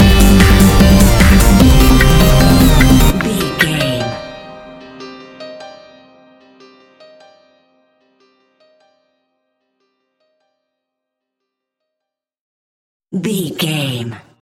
Sounds Like Trance Stinger.
Aeolian/Minor
Fast
driving
energetic
futuristic
hypnotic
drum machine
synthesiser
piano
electronic
uptempo